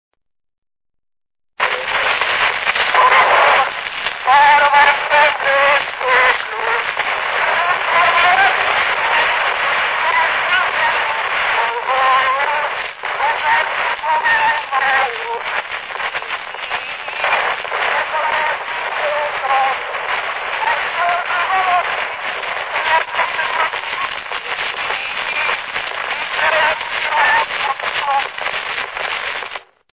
Dunántúl - Sopron vm. - Csepreg
Műfaj: Betlehemes
Stílus: 7. Régies kisambitusú dallamok